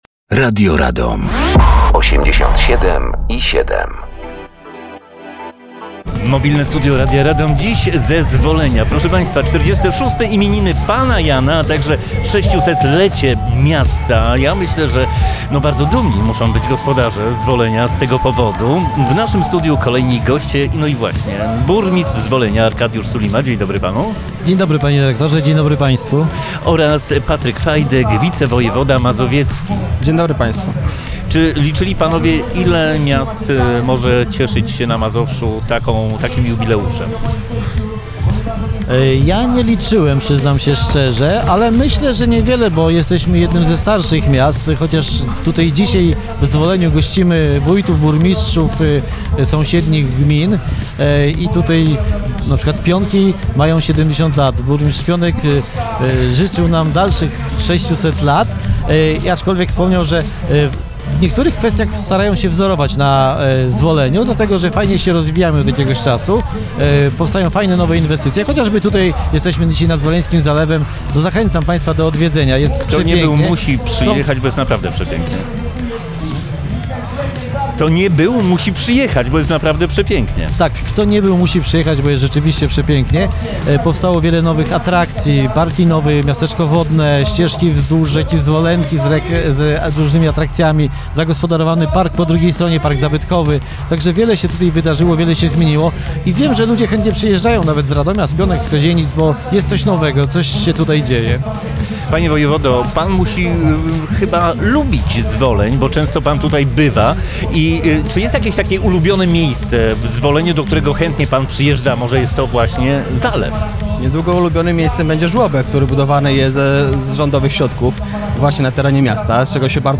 Mobilne Studio Radia Radom gościło w Zwoleniu podczas finałowego koncertu 46 Urodzin Pana Jana.
Gośćmi byli Arkadiusz Sulima Burmistrz Zwolenia i Patryk Fajdek Wicewojewoda Mazowiecki